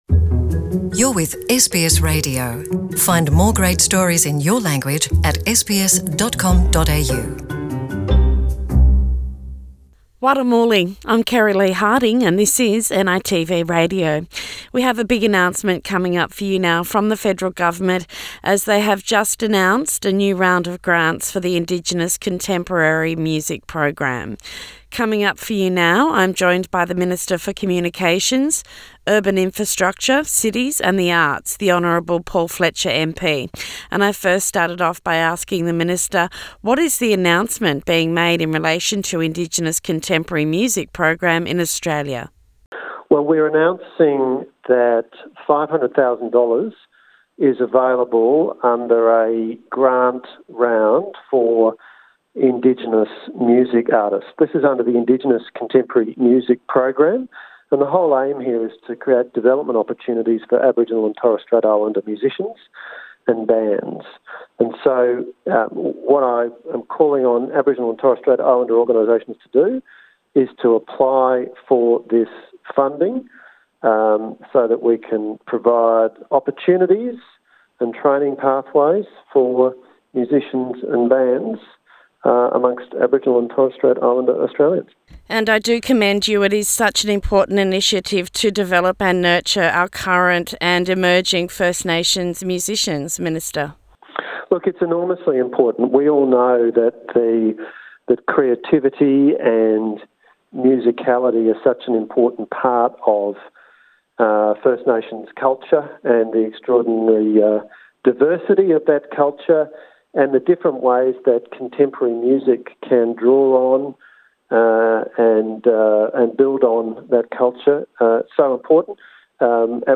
Interview with the Minister for Communications, Urban Infrastructure, Cities and the Arts the Honourable Paul Fletcher MP.